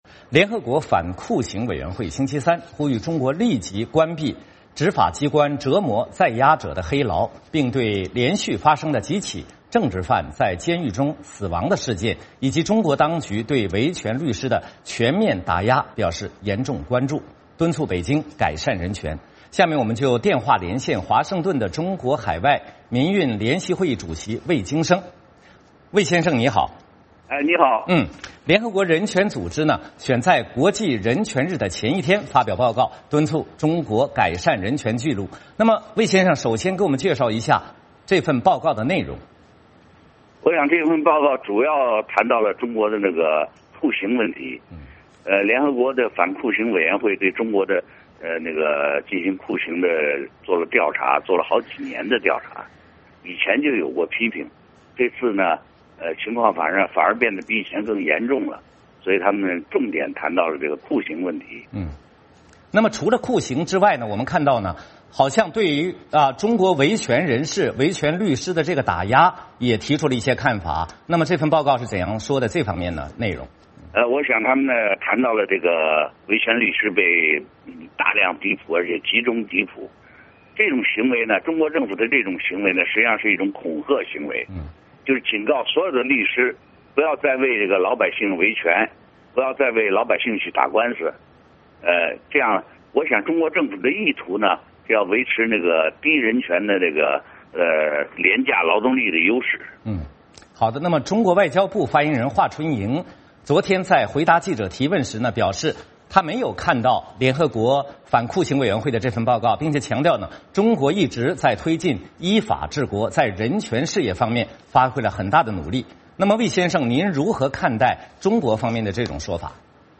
联合国反酷刑委员会星期三呼吁中国立即关闭执法机关折磨在押者的“黑牢”，并对连续发生的几起政治犯在监狱中死亡的事件，以及中国当局对维权律师的全面镇压表示严重关切，敦促北京改善人权。下面我们电话连线华盛顿的中国海外民运联席会议主席魏京生。